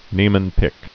(nēmən-pĭk)